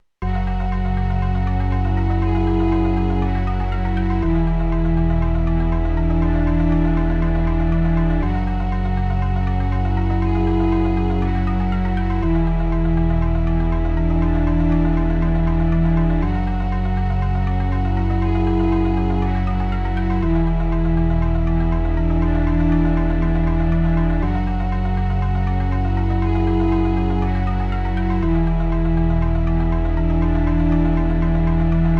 Spooky I feel like is the best type of game art.